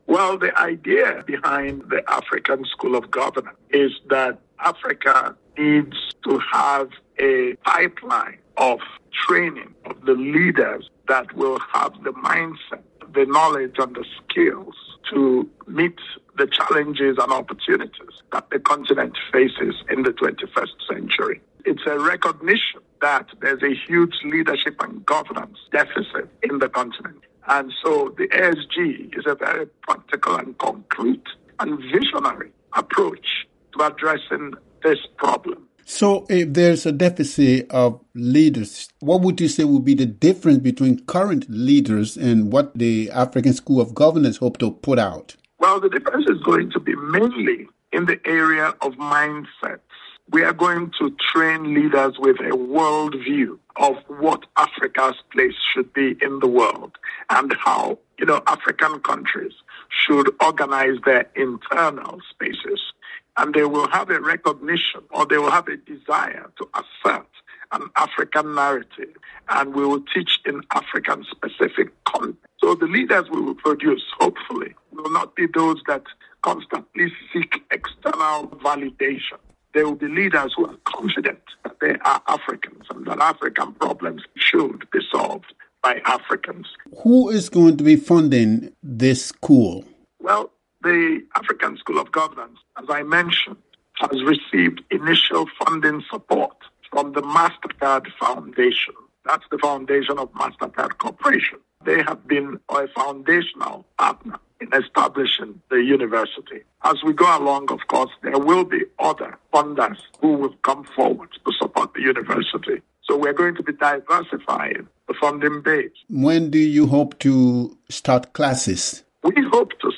Professor Kingsley Moghalu, a former deputy governor of the Central Bank of Nigeria, is the president of the African School of Governance which will be based in Kigali, Rwanda.